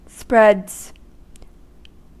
Ääntäminen
Ääntäminen US Haettu sana löytyi näillä lähdekielillä: englanti Käännöksiä ei löytynyt valitulle kohdekielelle. Spreads on sanan spread monikko.